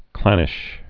(klănĭsh)